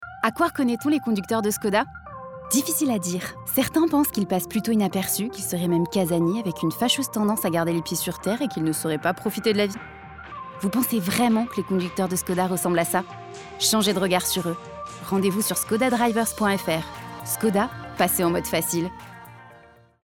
Sua entrega segura e acolhedora se adapta a comerciais, narrações e rádio, tornando-a uma escolha versátil para marcas que buscam serviços profissionais de locução.
Automotivo
Microfone: Neumann TLM 103